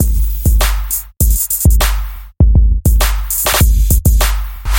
RAW RnB Drumloop
描述：这是一个2小节（2x4 / 4）100 bpm的Rnb鼓循环。
Tag: 100 bpm RnB Loops Drum Loops 827.00 KB wav Key : Unknown